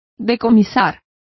Complete with pronunciation of the translation of confiscating.